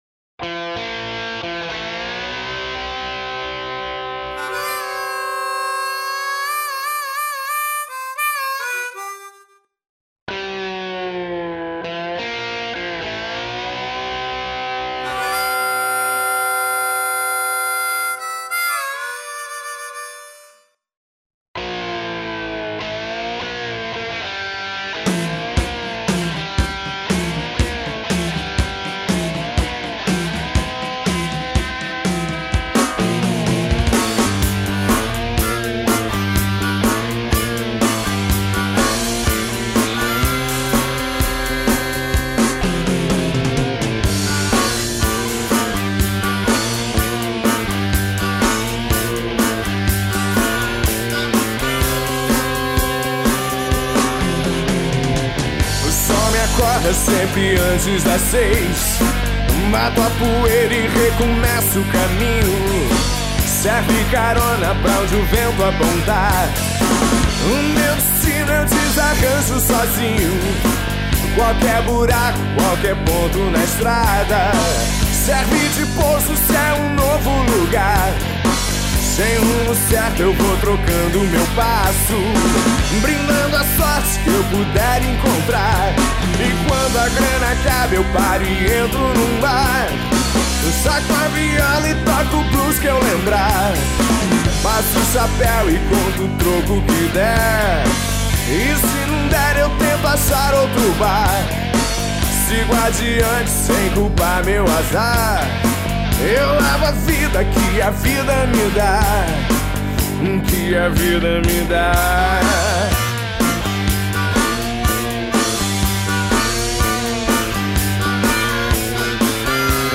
Rock & Roll – 2007
esbanjando gaita para todos os lados!